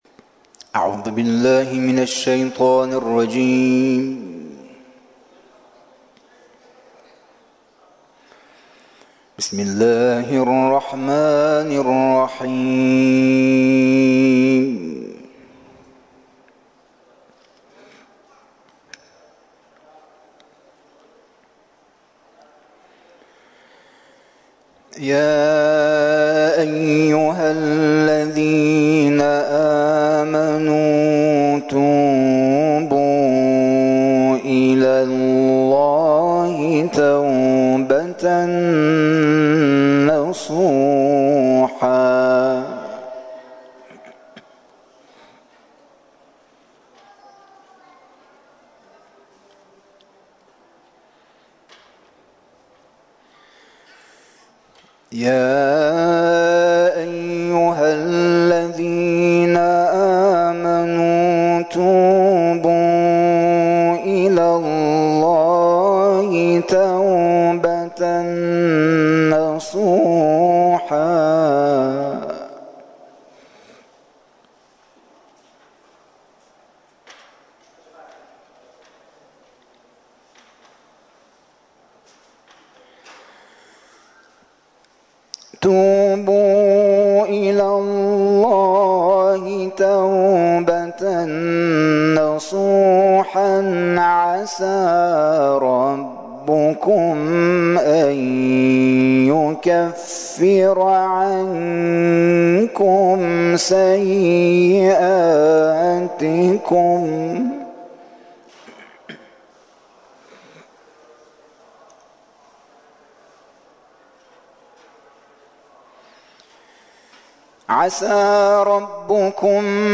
محافل و مراسم قرآنی
تلاوت قرآن کریم